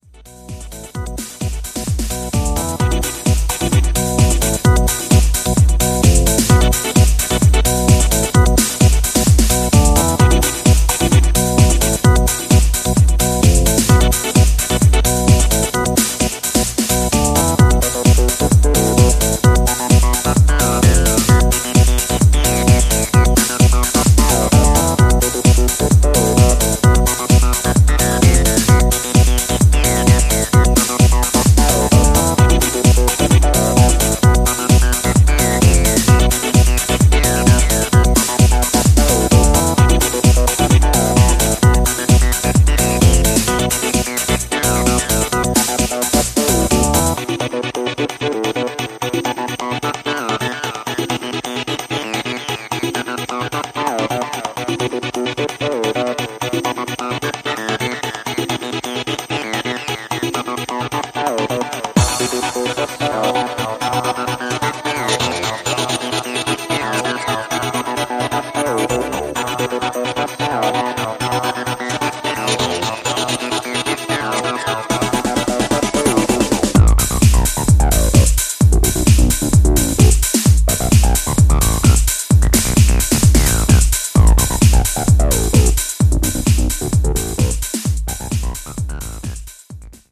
B面の同系統のアシッディーなプログレッシヴ・ハウス・エディットも超強力！